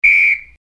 Звуки спорта
Звук свистка судьи на спортивном мероприятии